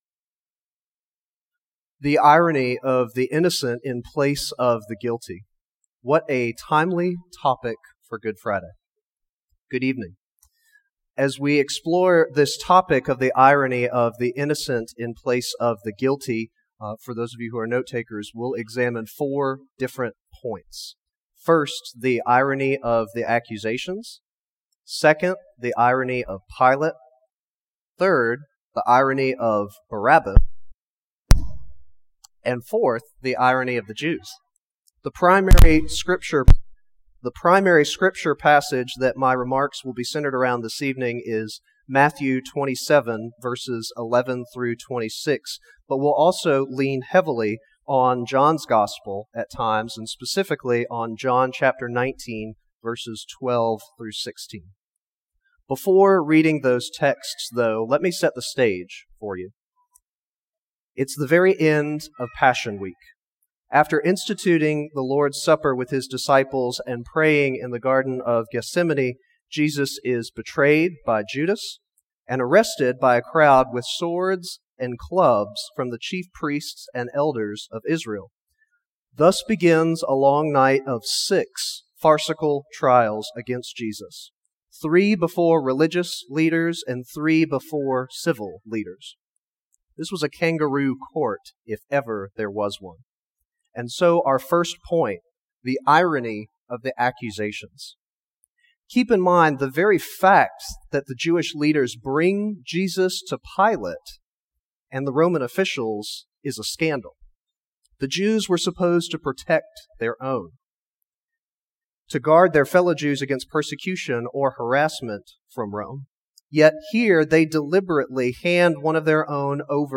Sermons | Great Commission Baptist Church
Good Friday service. 2024